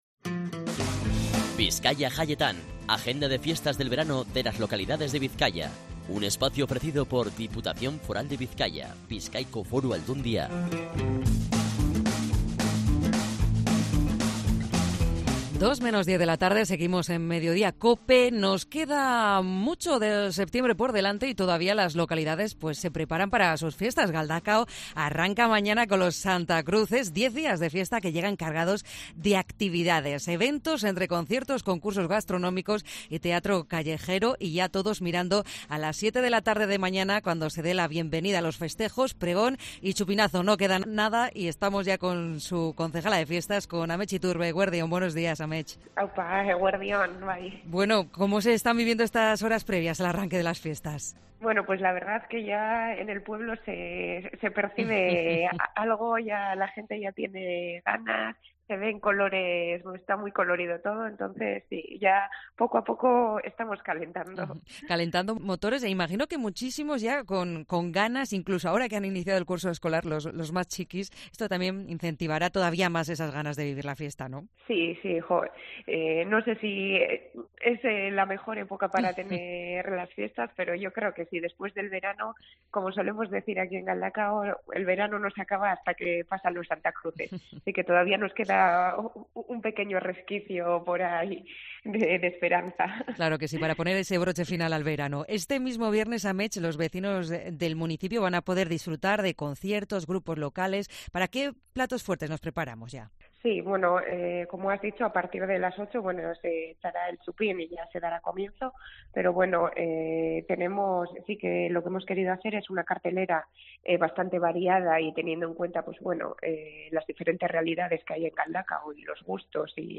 En una entrevista en COPE Euskadi, Iturbe destaca "las ganas y colores" que ya se viven y ven en las horas previas a las fiestas.